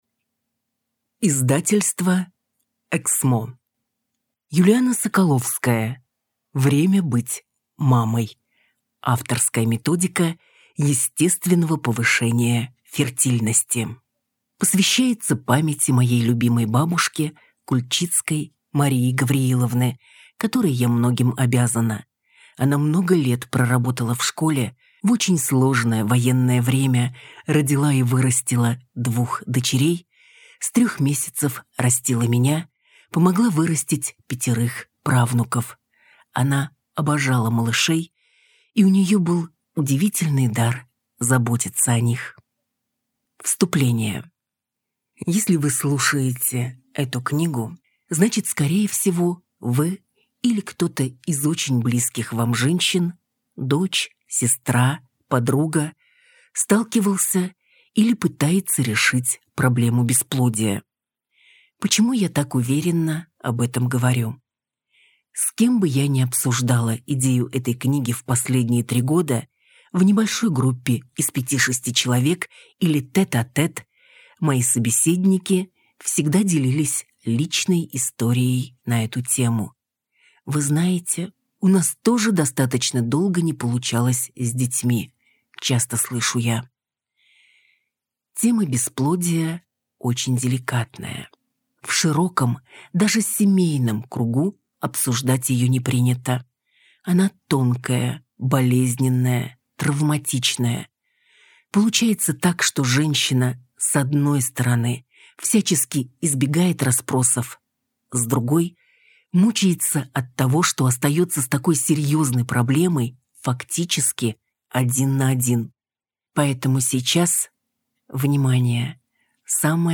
Аудиокнига Время быть мамой. Авторская методика естественного повышения фертильности | Библиотека аудиокниг